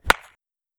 baseball-hitting-bat-set-2.wav